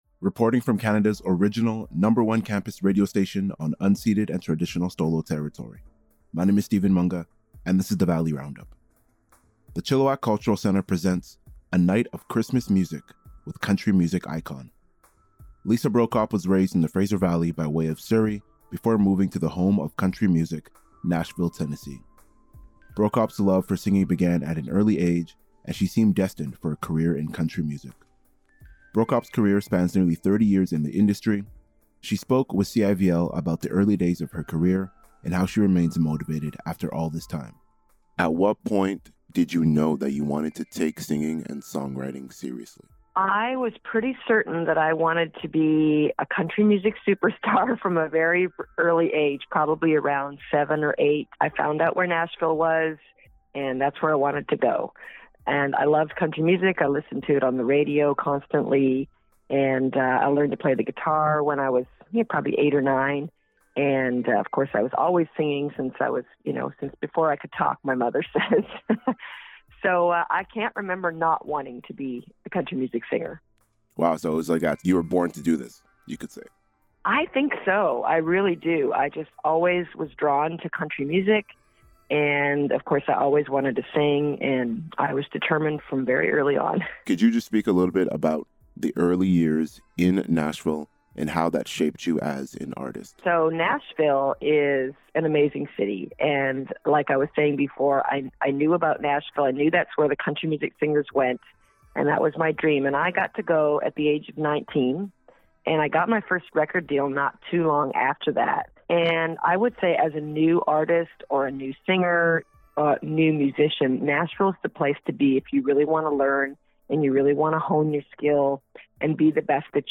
In an interview with CIVL, Brokop shared more about the early days of her career and how she remains motivated musically after all this time.
LISA-BROKOP-INTERVIEW-EXP-DEC-10.mp3